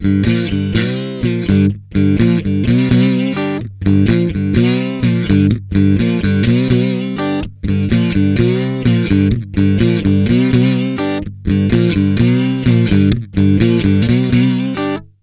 Index of /90_sSampleCDs/Best Service ProSamples vol.17 - Guitar Licks [AKAI] 1CD/Partition D/VOLUME 007